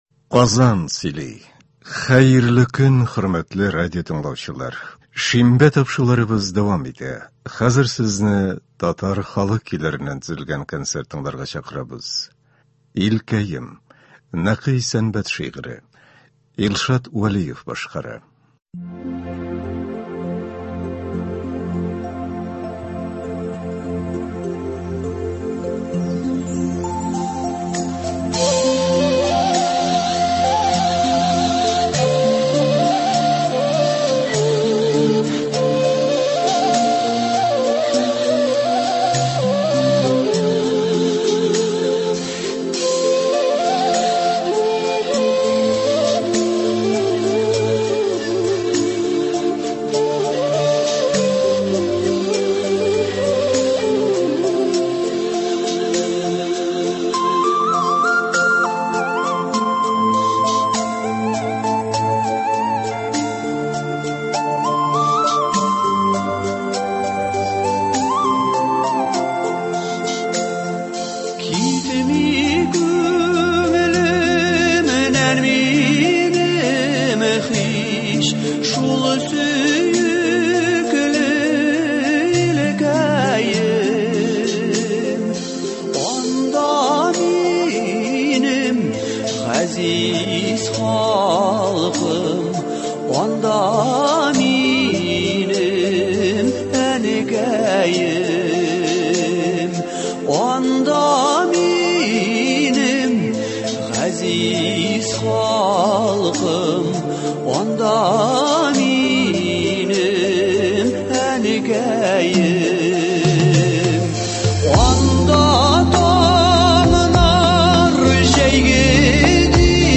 Татар халык җырлары (13.11.21)
Бүген без сезнең игътибарга радио фондында сакланган җырлардан төзелгән концерт тыңларга тәкъдим итәбез.